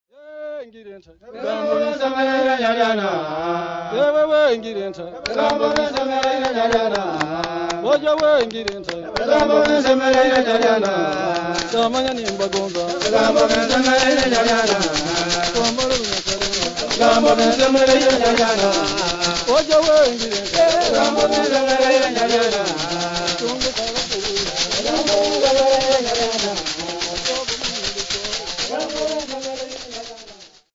Folk music
Field recordings
sound recording-musical
"We were pleased with your words" they reply in chorus. The answer to an invitation to a drinking party.
Drinking song, with Ebinyege leg rattles and clapping.
96000Hz 24Bit Stereo